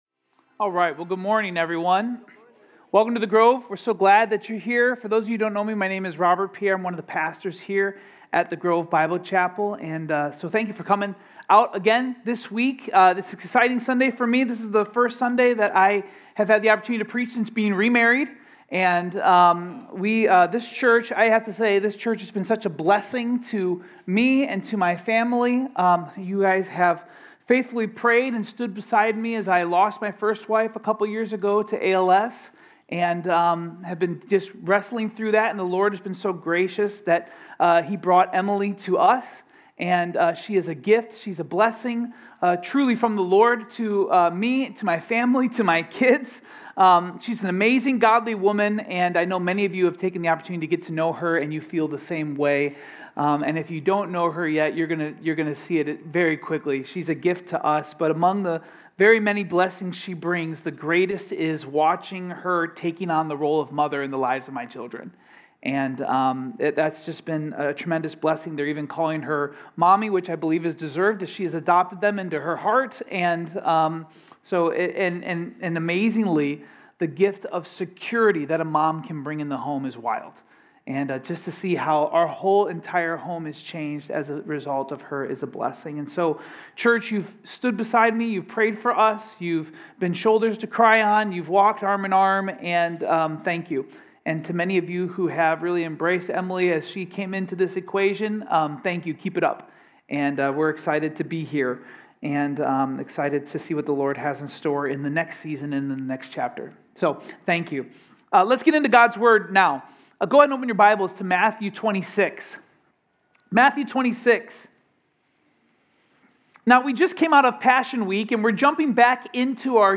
Home Sermons Cornerstone